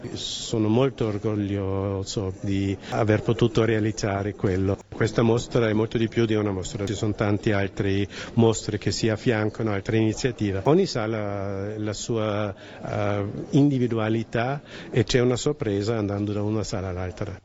Presente alla conferenza stampa che ha preceduto l’apertura ufficiale delle mostre